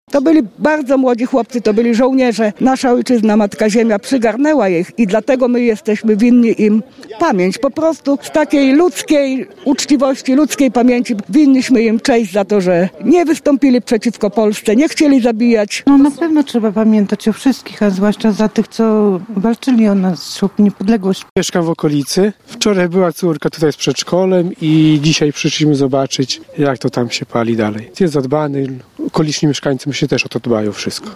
mowia_mieszkancy_bialej_podlaskiej_.mp3